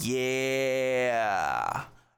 Yeah.wav